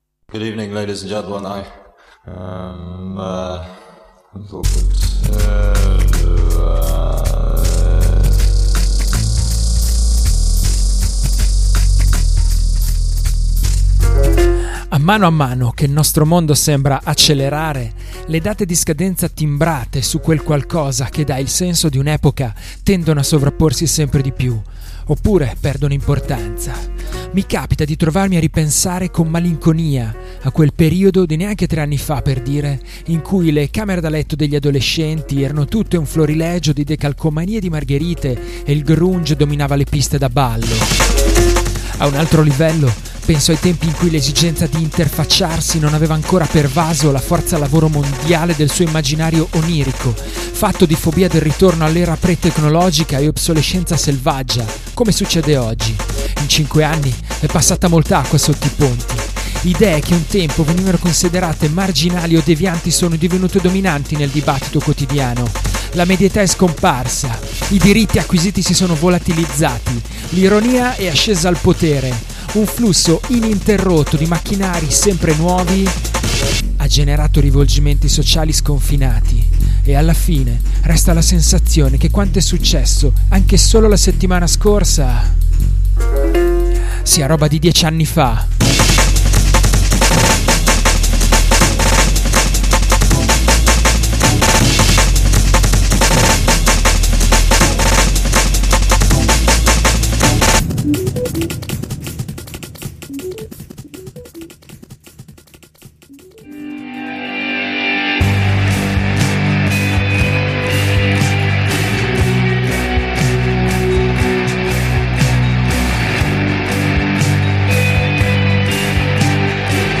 Podcast di novità indiepop, indie rock, shoegaze, post-punk, lo-fi e twee!